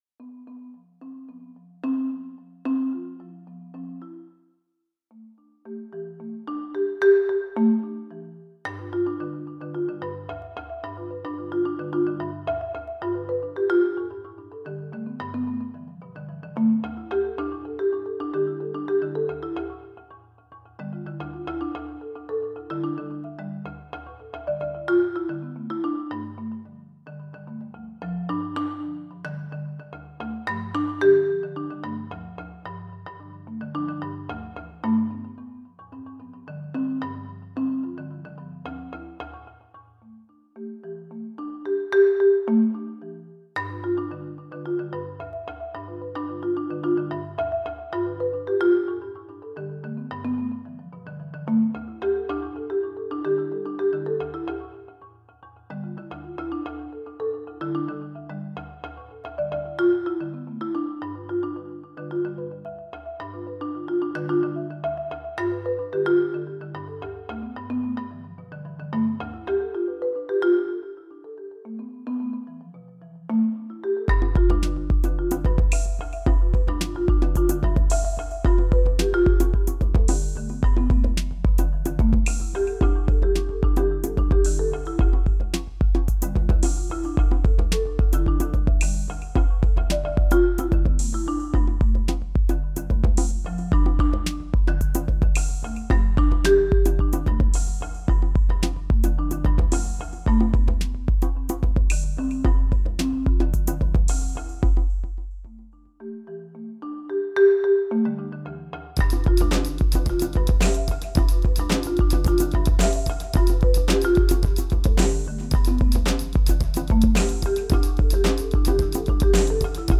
A great sounding Marimba with a range of 5 octaves and 3 articulations.
marimba_williamsburg.mp3